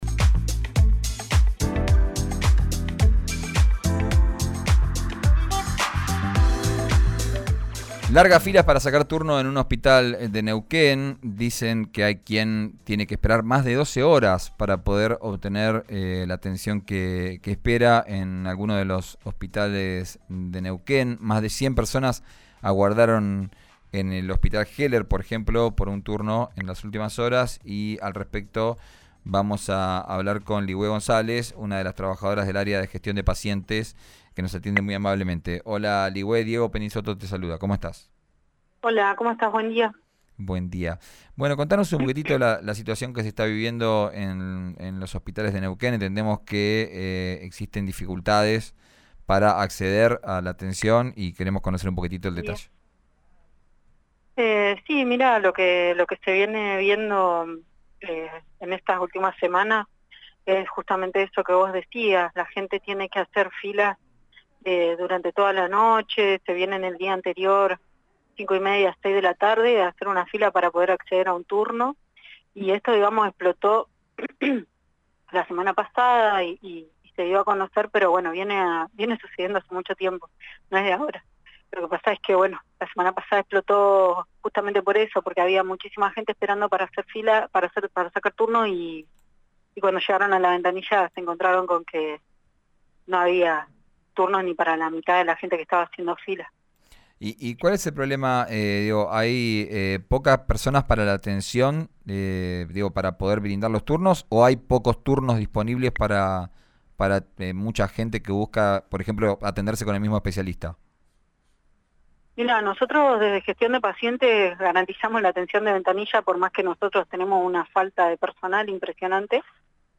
en el aire de RÍO NEGRO RADIO